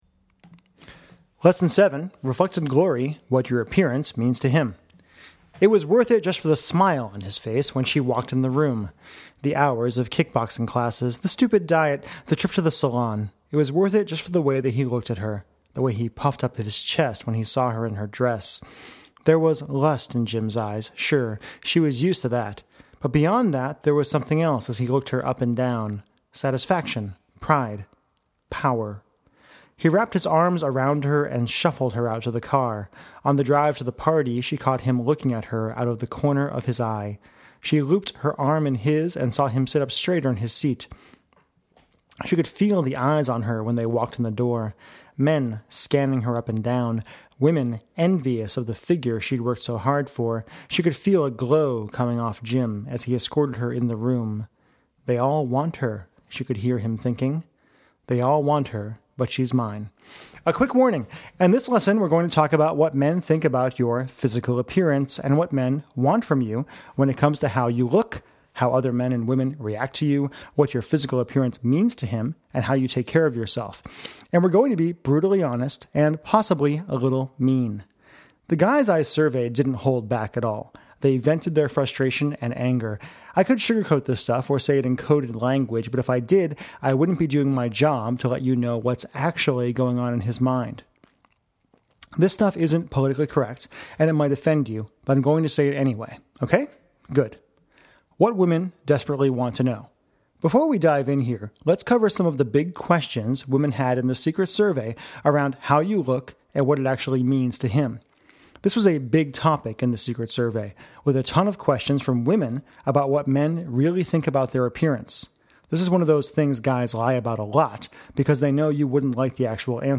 Secret+Survey+Lesson+7+Reflected+Glory.mp3